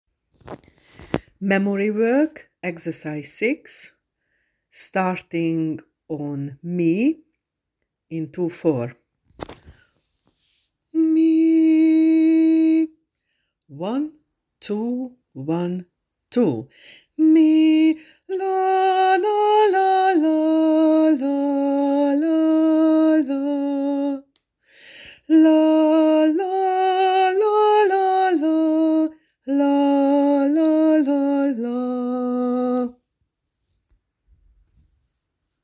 6.Listen to the following short melody (5 times).